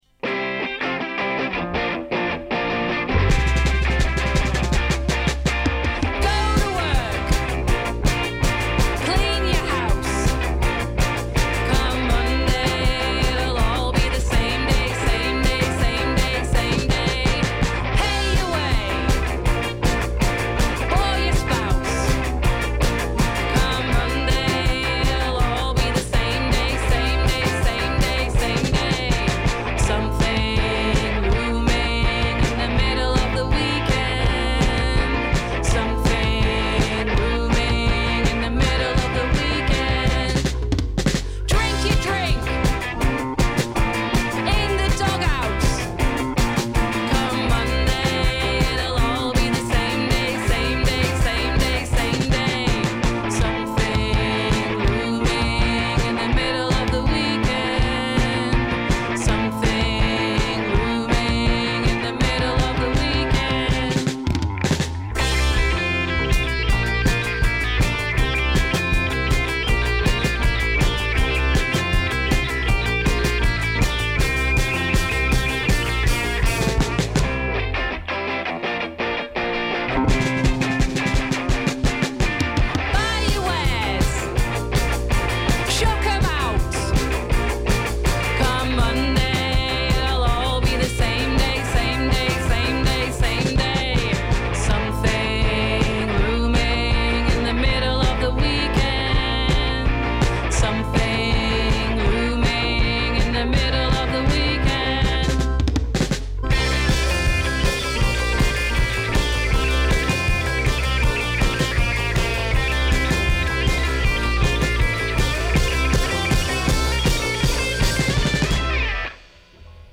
ode to the itchy serenade and the wistful lament.